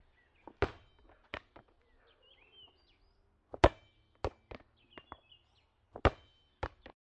跳球
描述：球弹跳。平坦的篮球在水泥表面上弹跳。使用它的胶囊麦克风使用变焦h6进行录制。